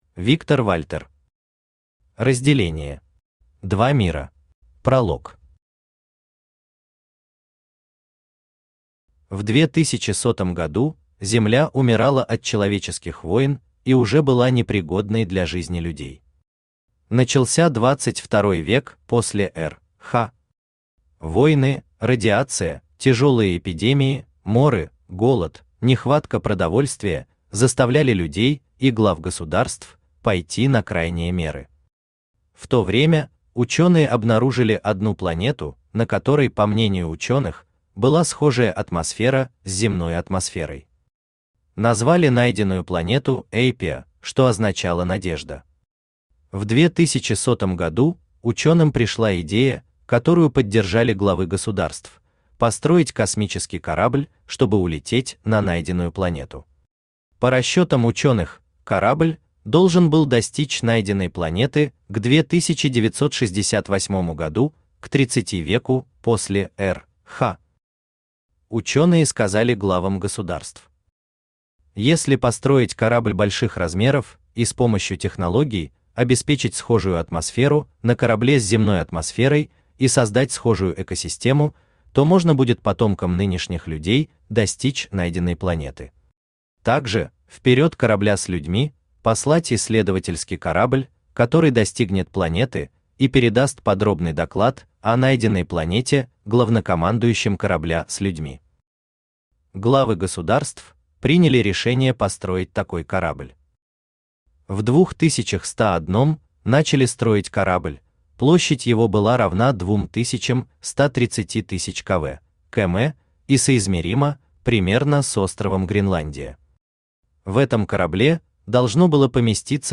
Аудиокнига Разделение. Два мира | Библиотека аудиокниг
Два мира Автор Виктор Вальтер Читает аудиокнигу Авточтец ЛитРес.